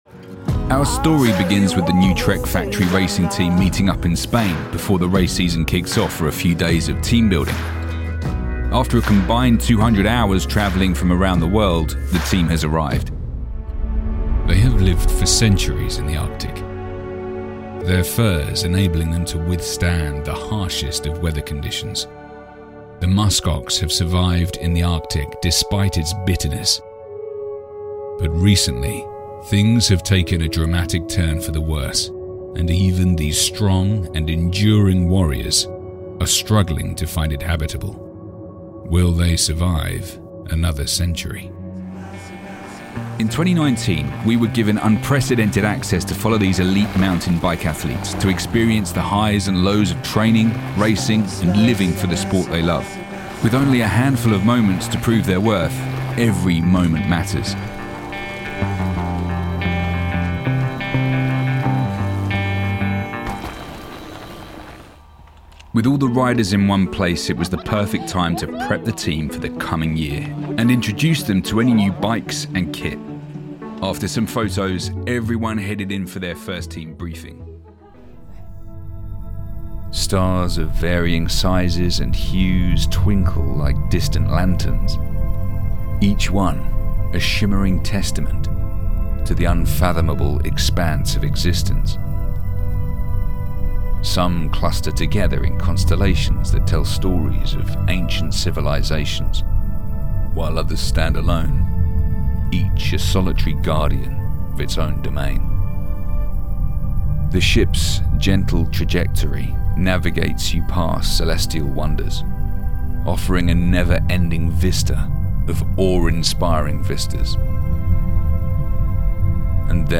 Englisch (Britisch)
Kommerziell, Tief, Unverwechselbar, Verspielt, Cool
Unternehmensvideo